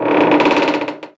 trapdooropen.wav